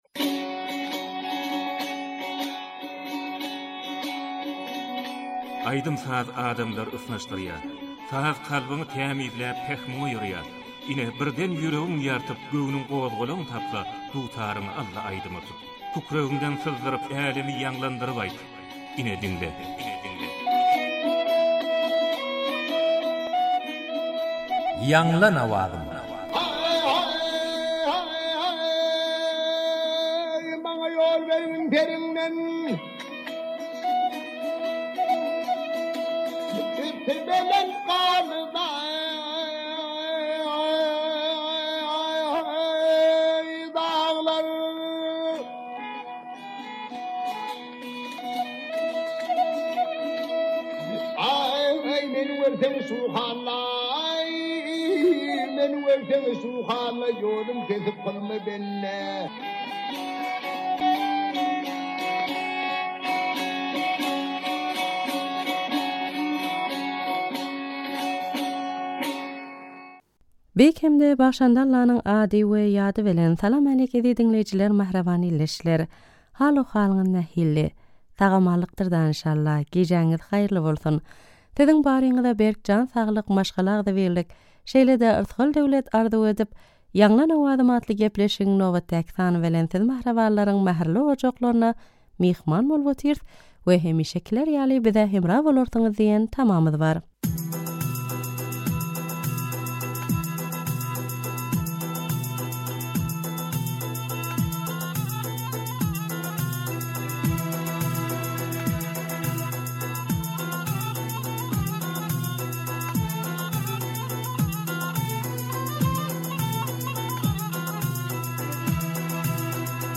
turkmen owaz aýdym